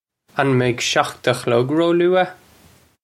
Pronunciation for how to say
Un may-ukh shokht uh khlug roh-loo-uh?
This is an approximate phonetic pronunciation of the phrase.